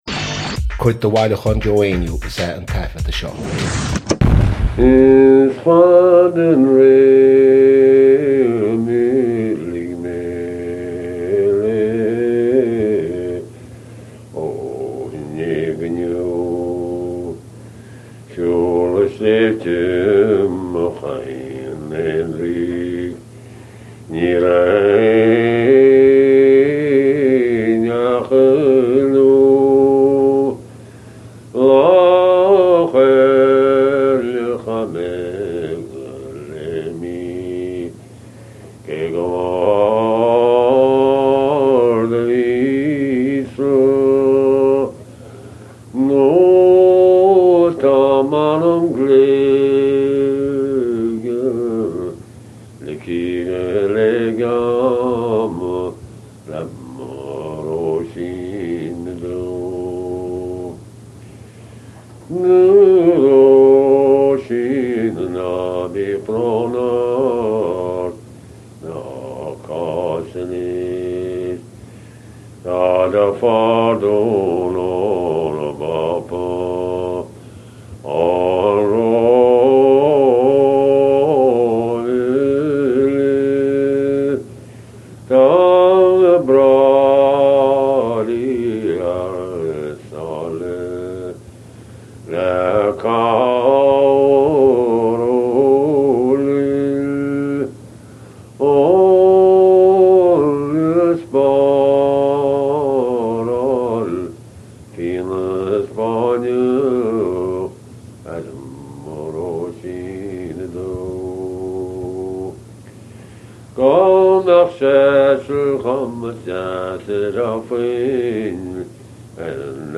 • Catagóir (Category): song.
• Ainm an té a thug (Name of Informant): Joe Heaney.
• Ocáid an taifeadta (Recording Occasion): concert.
Before he began singing, Joe was in the habit of reciting the first stanza of Mangan’s translation to the audience, as a way of putting them into the right frame of mind: